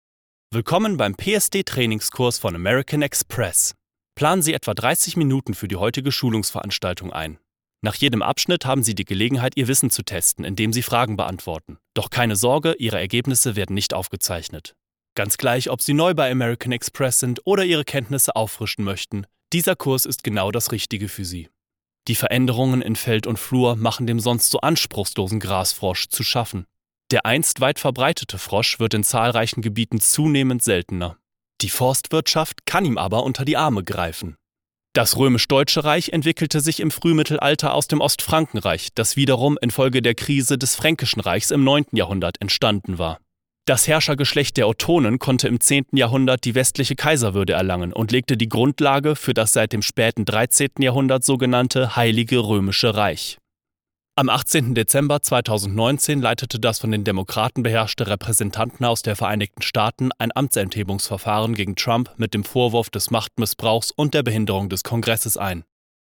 Almanca Seslendirme
Erkek Ses